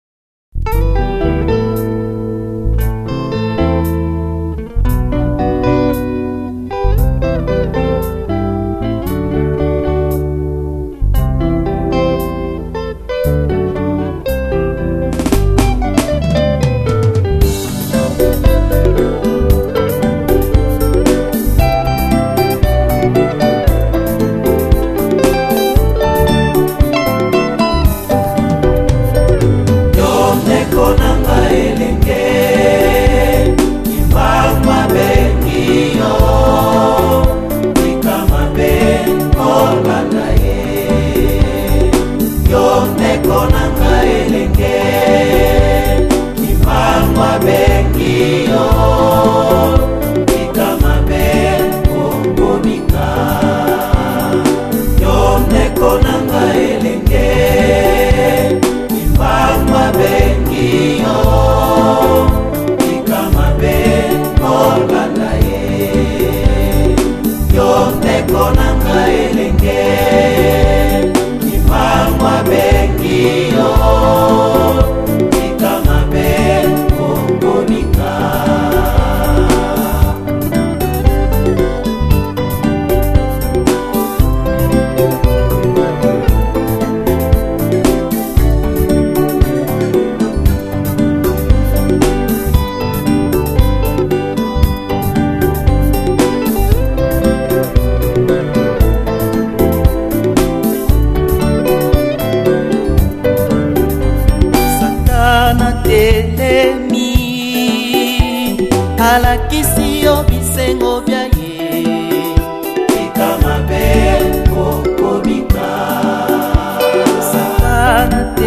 Liste des cantiques